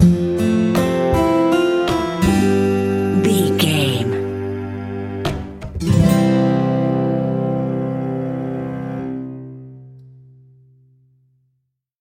Ionian/Major
Slow
tranquil
mellow
cello
acoustic guitar
percussion